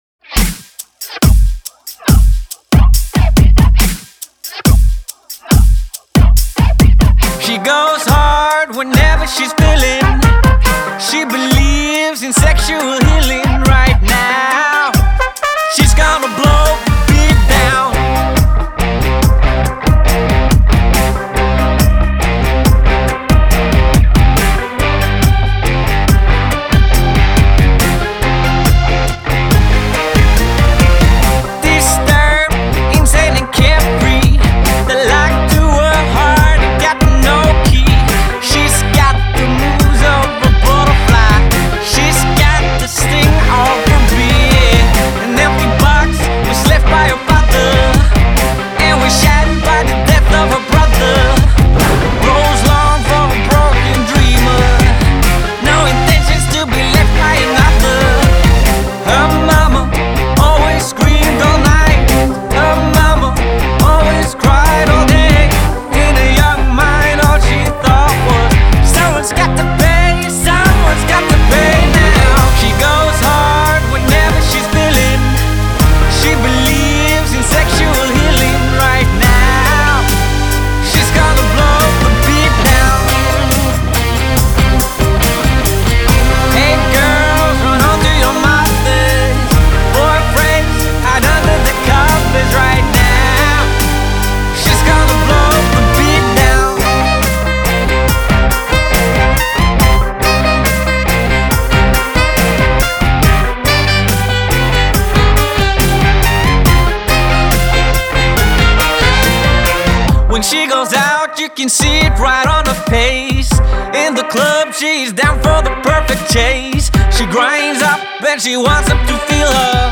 singer/songwriter
rock pop edge